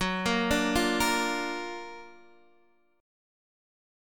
F#+M7 chord